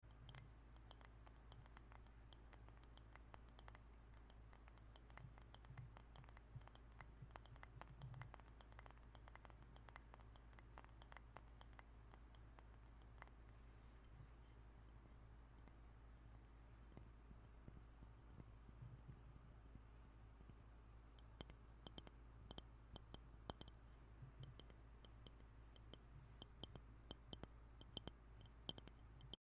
The sharp taps of glass sound effects free download
The sharp taps of glass = instant tingles ✨ This coaster has the perfect sound—clean, crisp, and oddly satisfying.